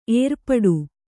♪ ērpaḍu